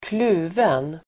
Uttal: [²kl'u:ven]